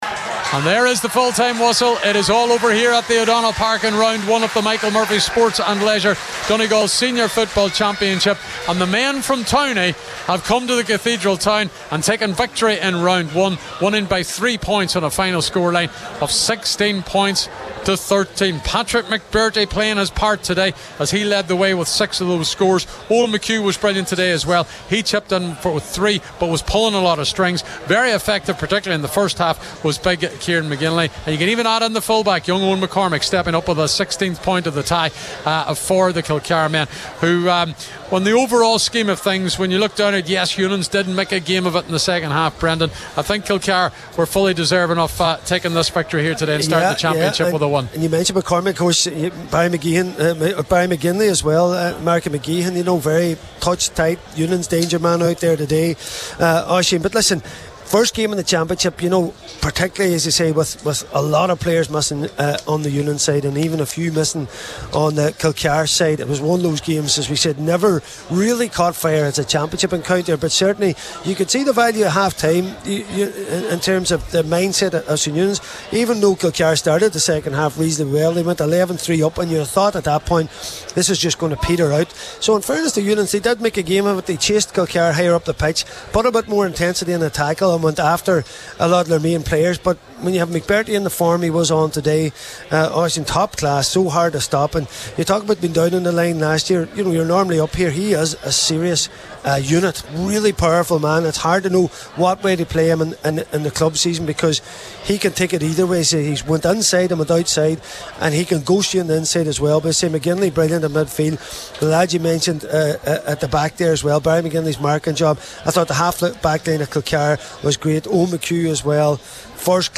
live at full time in Letterkenny…